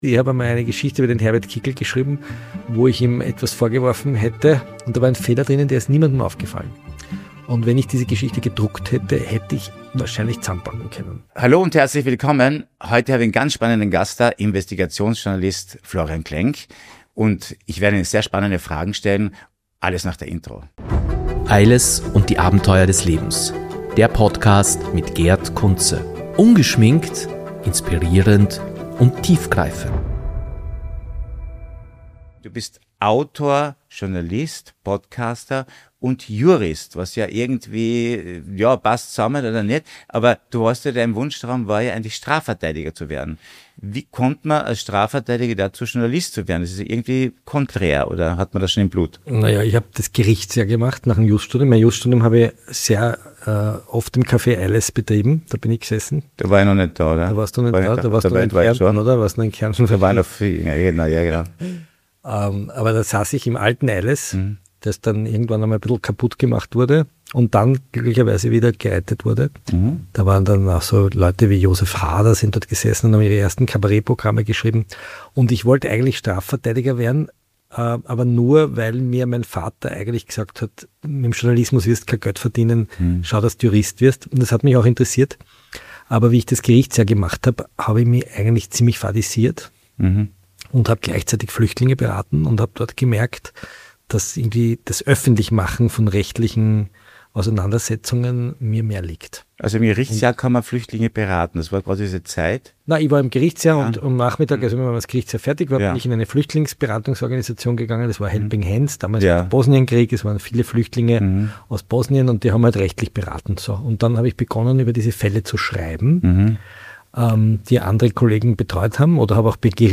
Außerdem geht es um die Verantwortung von Medien, Fehler im Journalismus und darum, wie sich Politik und Öffentlichkeit durch Social Media verändert haben. Ein Gespräch über Politik, Medien, Macht und Wahrheit.